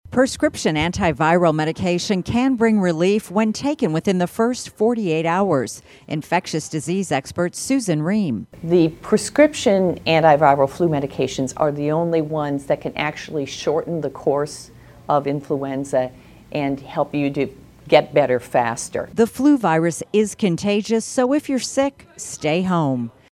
Health Reporter